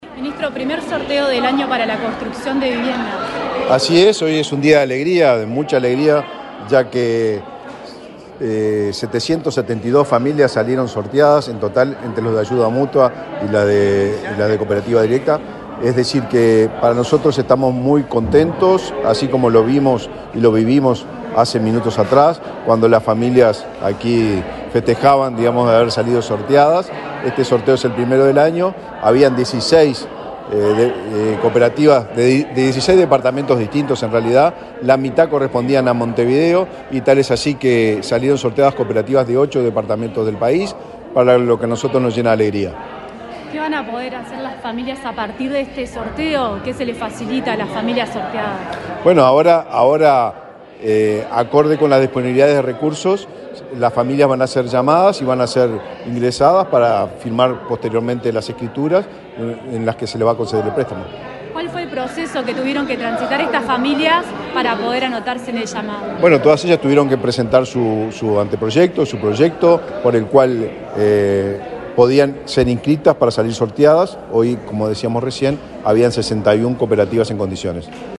Declaraciones del ministro de Vivienda, Raúl Lozano
Declaraciones del ministro de Vivienda, Raúl Lozano 08/08/2024 Compartir Facebook X Copiar enlace WhatsApp LinkedIn El ministro de Vivienda, Raúl Lozano, participó, este jueves 8 en la sede de su cartera, del primer sorteo de este año, de cupos para construcción de viviendas cooperativas. Luego, dialogó con Comunicación Presidencial acerca del alcance del beneficiio.